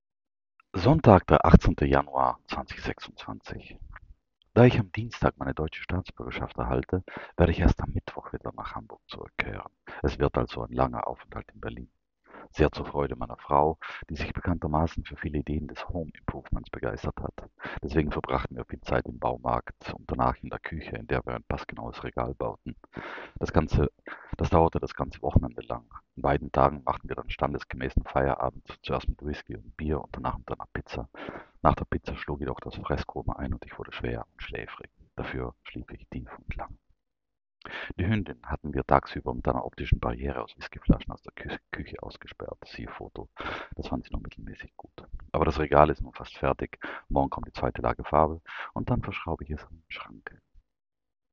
[So, 18.1.2025 - hämmern und verschrauben] - es regnet